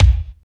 25.07 KICK.wav